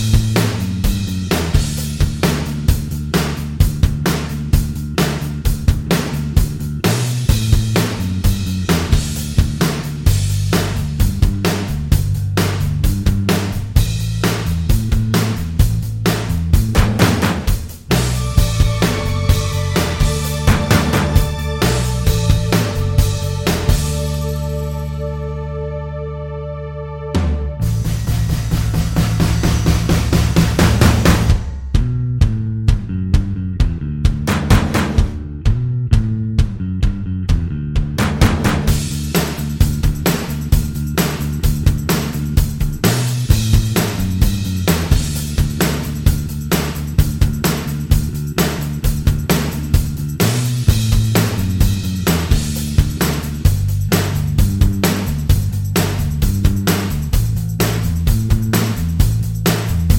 Minus Main Guitar For Guitarists 2:15 Buy £1.50